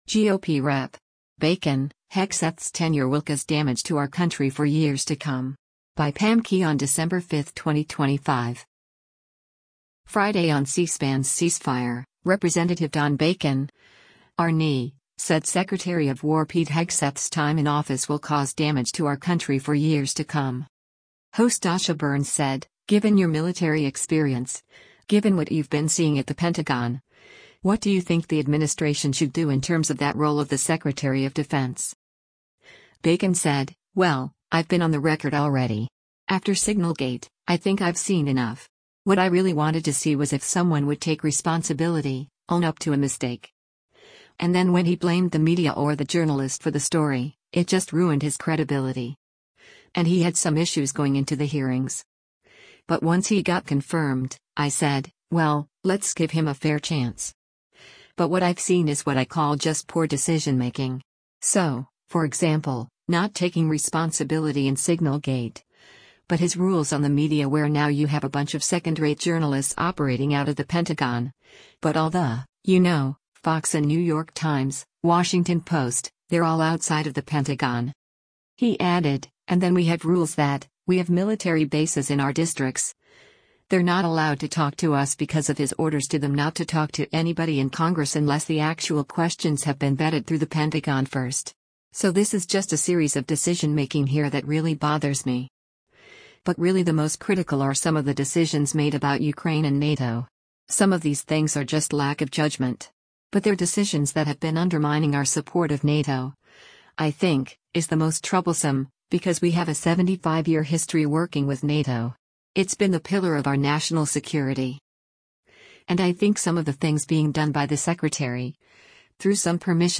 Friday on C-SPAN’s “Ceasefire,” Rep. Don Bacon (R-NE) said Secretary of War Pete Hegseth’s time in office will “cause damage to our country for years to come.”